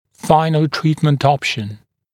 [‘faɪnl ‘triːtmənt ‘ɔpʃn][‘файнл ‘три:тмэнт ‘опшн]окончательно выбранный вариант лечения